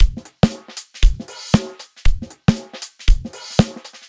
Nun jener, den ich mit dem kostenlos für Windows erhältlichen Filter Plugin Phuturetone Philteroid und dessen envelope follower bearbeitete habe:
huellkurve_sound_envelope_follower_2.mp3